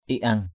/iʔ-ʌŋ/ (d.) ông táo = génie du foyer. home genius.